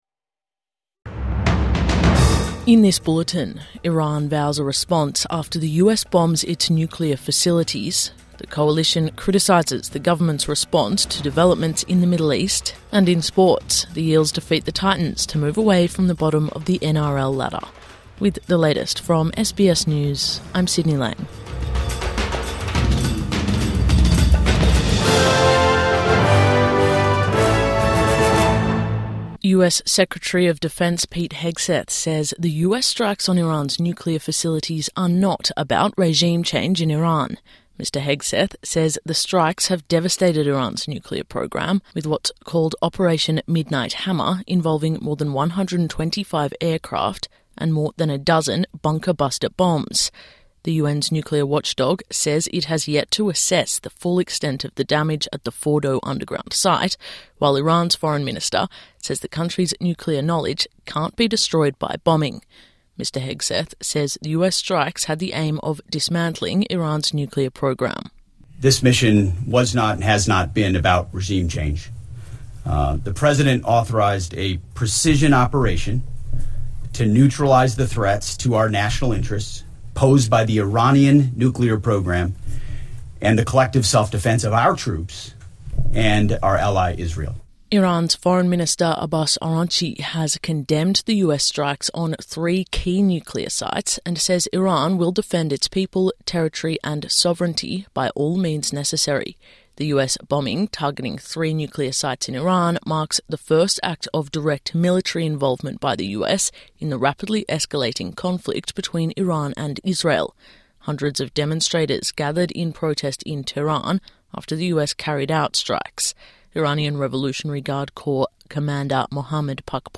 Iran condemns US strikes on nuclear sites | Morning News Bulletin 23 June 2025